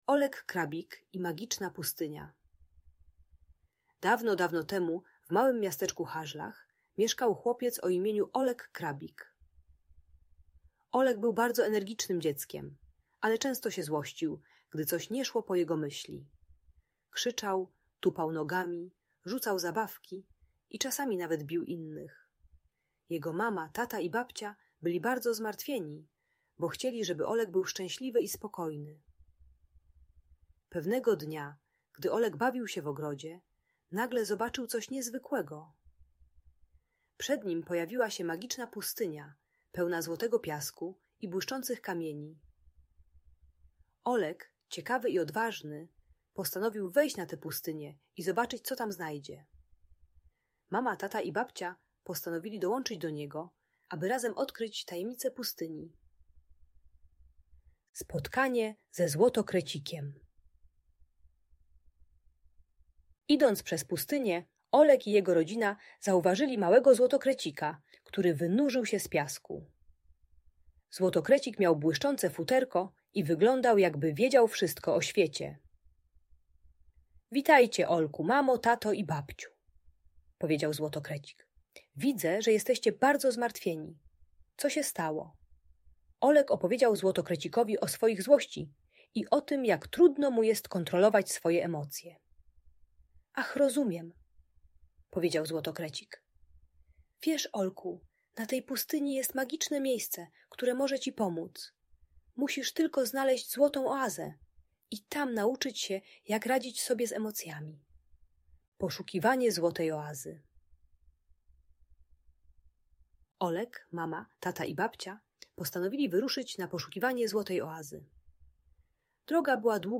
Opowieść o Olku Krabiku i Magicznej Pustyni - Audiobajka dla dzieci